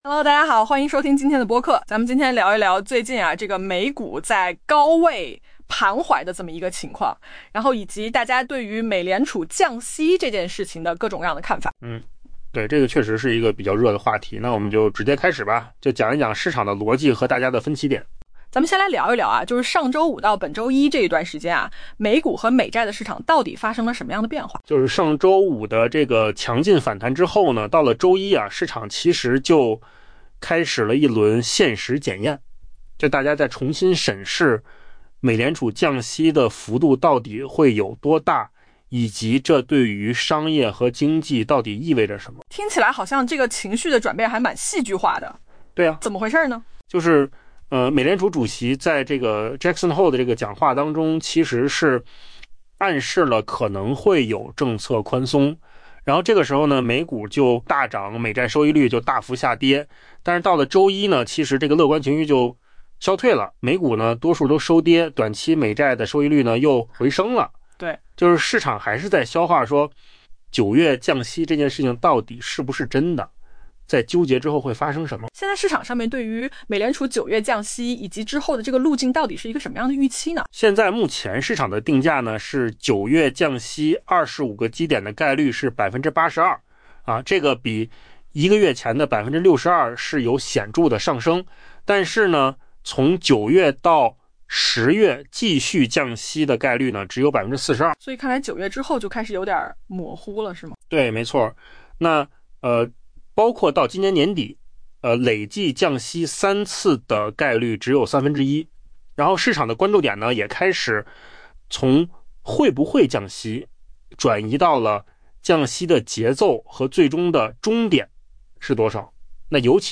AI播客：换个方式听新闻 下载mp3
音频由口罩空间生成